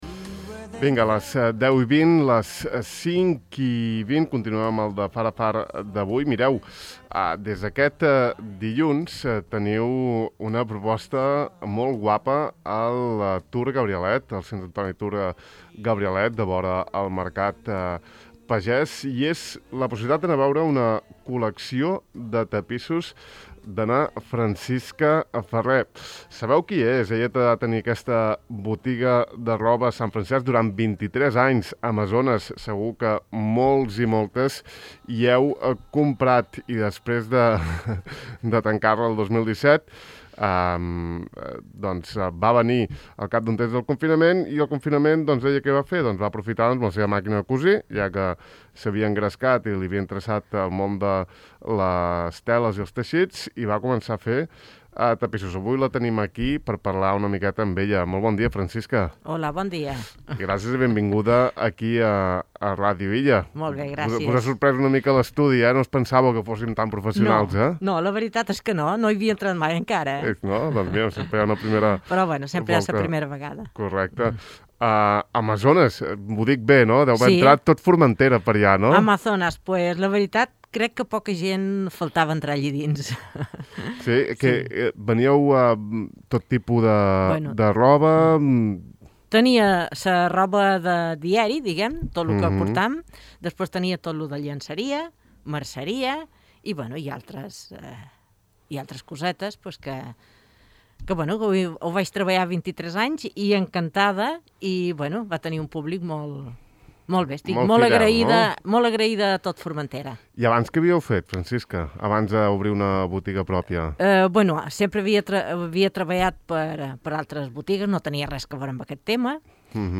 Ahir vam conversar amb ella pausadament sobre la seva afició i obra.